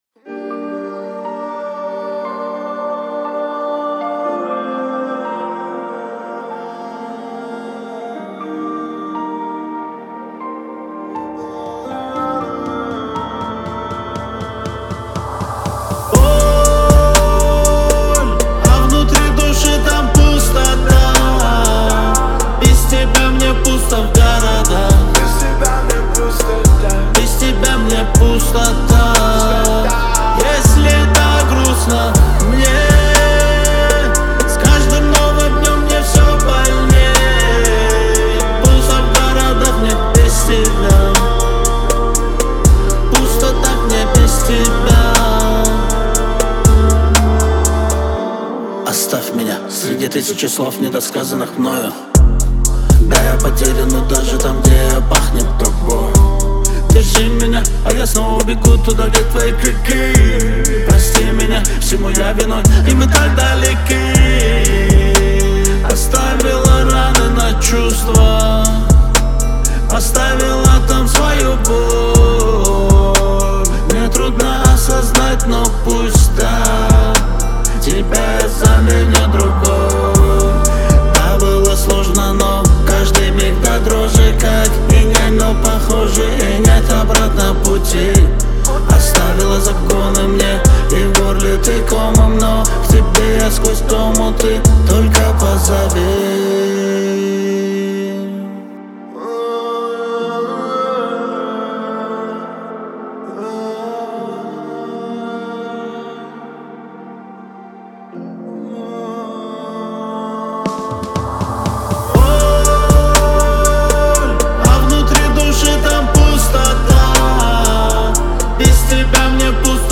поп-рок